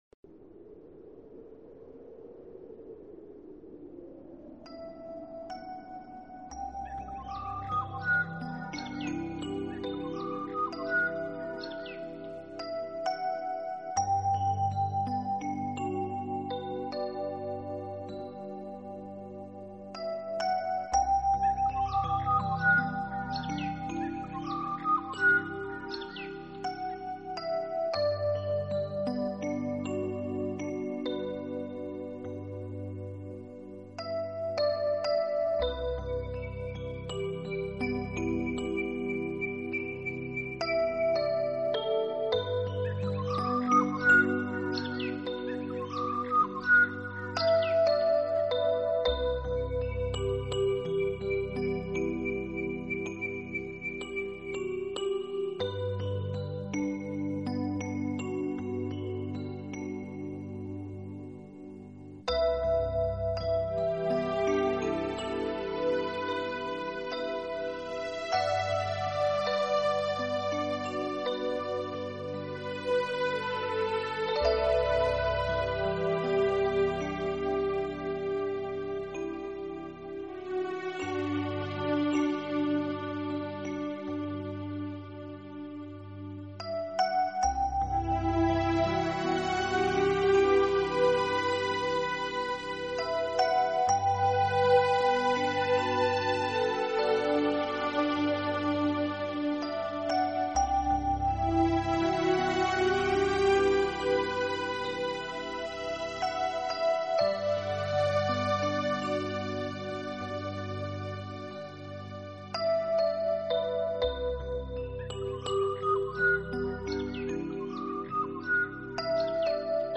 Genre..........: New Age
helps create a relaxing and inspiring atmosphere.